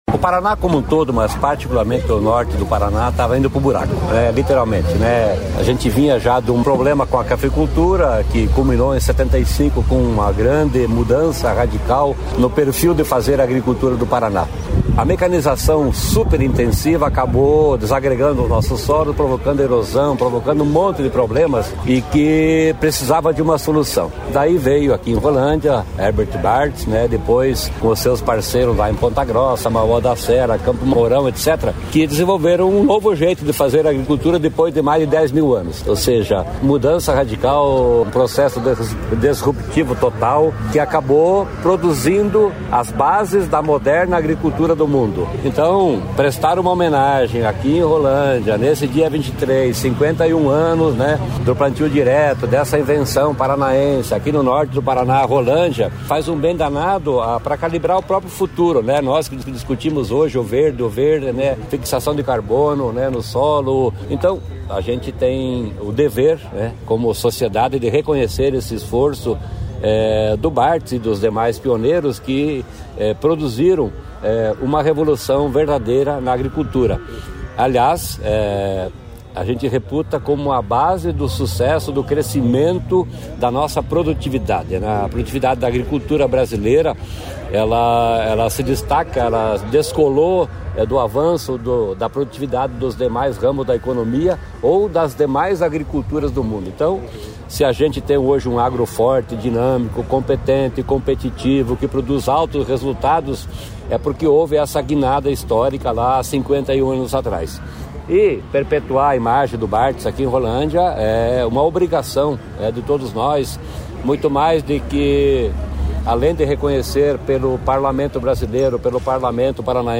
Sonora do secretário da Agricultura e do Abastecimento, Norberto Ortigara, sobre a técnica do plantio direto no Paraná